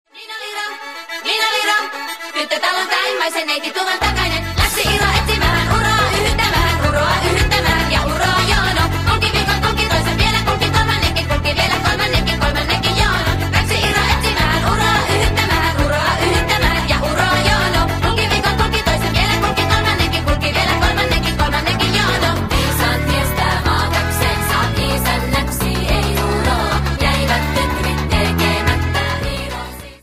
energetic high speed singing